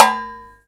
trayhit1.ogg